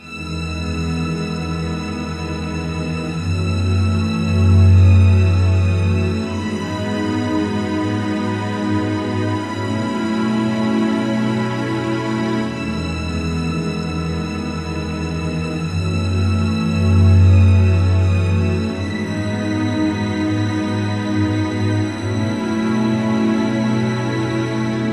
缓慢的民谣弦乐节奏
描述：8个无缝小节的交响乐弦乐旋律。
Tag: 85 bpm Orchestral Loops Strings Loops 3.80 MB wav Key : F